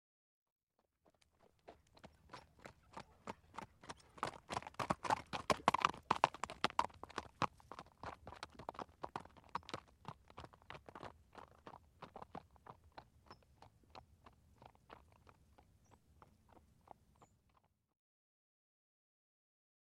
دانلود آهنگ اسب 94 از افکت صوتی انسان و موجودات زنده
دانلود صدای اسب 94 از ساعد نیوز با لینک مستقیم و کیفیت بالا
جلوه های صوتی